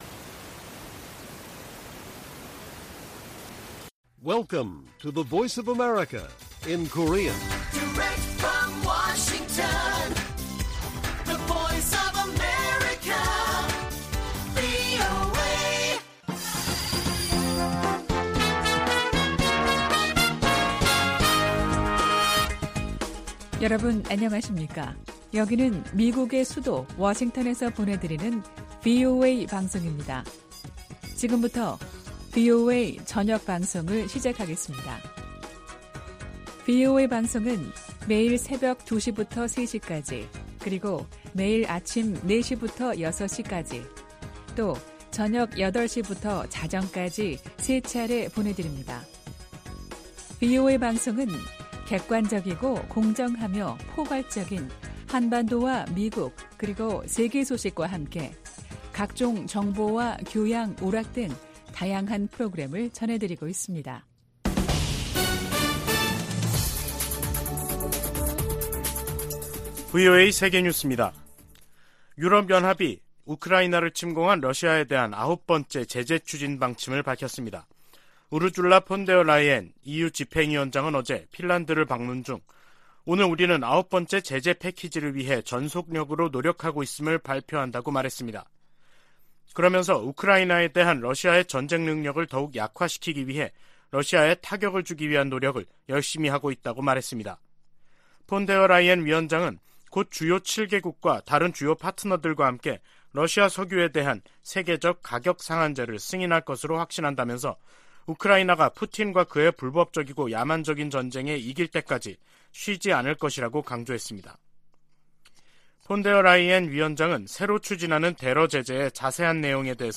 VOA 한국어 간판 뉴스 프로그램 '뉴스 투데이', 2022년 11월 25일 1부 방송입니다. 미국 의회에서 북한의 도발을 방조하는 ‘세컨더리 제재’등으로 중국에 책임을 물려야 한다는 요구가 거세지고 있습니다. 미국 고위 관리들이 최근 잇따라 북한 문제와 관련해 중국 역할론과 책임론을 거론하며 중국의 협력 의지를 시험하고 있다는 전문가 분석이 제기됐습니다.